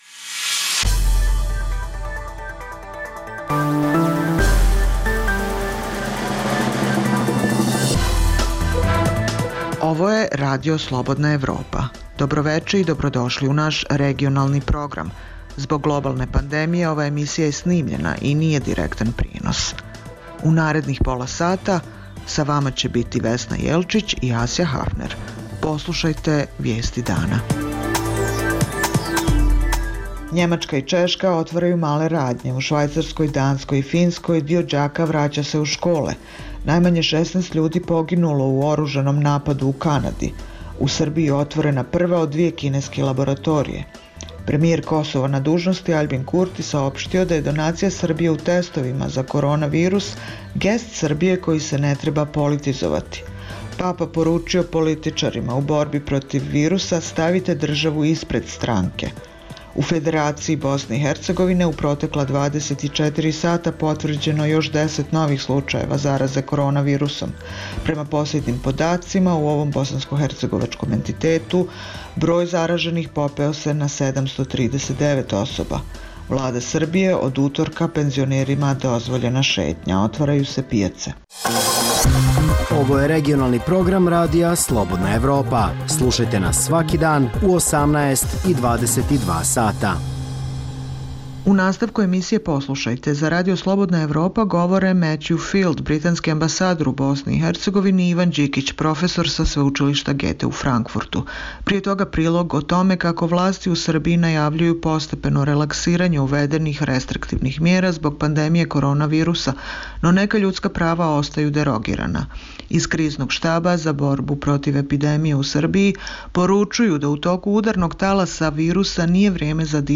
Zbog globalne pandemije, ova je emisija unapred snimljena i nije direktan prenos. Nemačka i Češka otvaraju male radnje; u Švajcarskoj, Danskoj i Finskoj se deo đaka vraća u škole. Najmanje 16 ljudi poginulo u oružanom napadu u Kanadi.